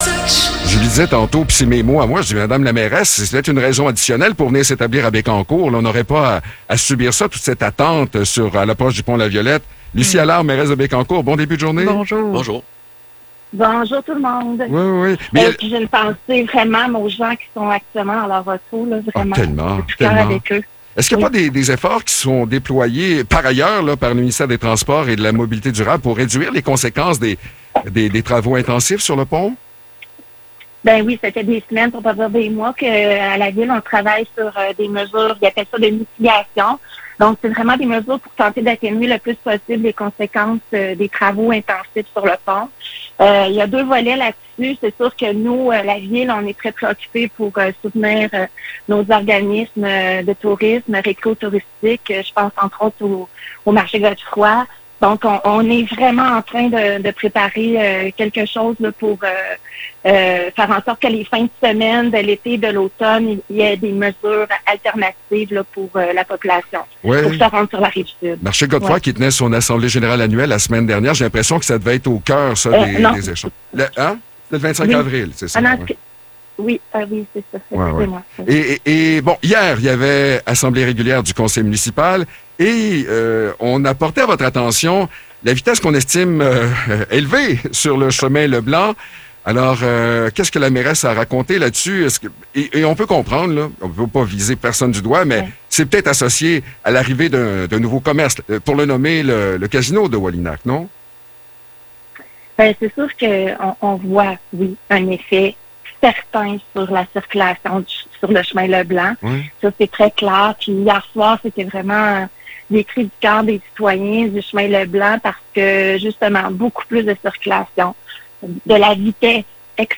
Échange avec la mairesse de Bécancour